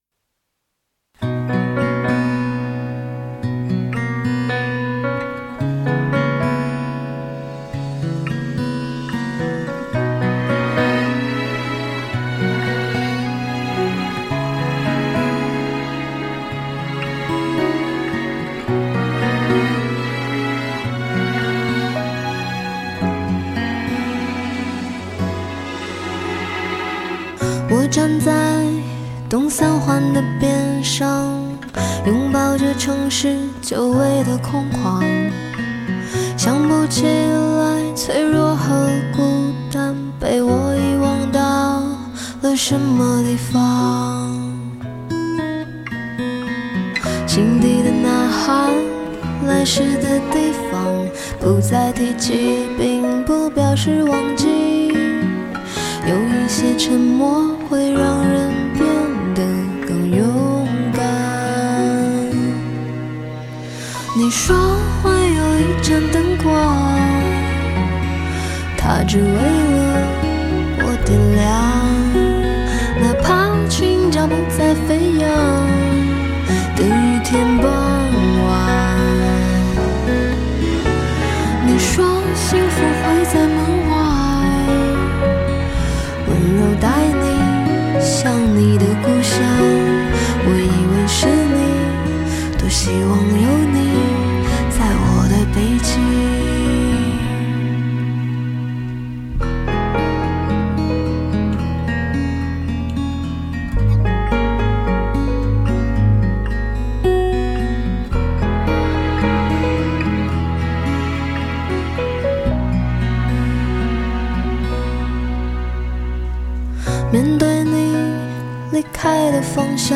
在音乐上有了更精准的把握能力，整张专辑更成熟、大气、深邃、丰富。